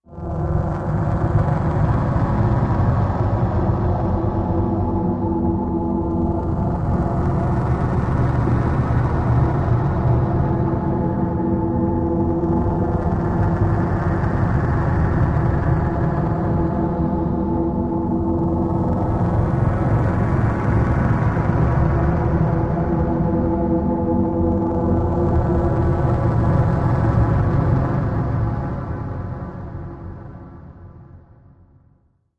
Cave Breath Deep Cave Wind Moving Texture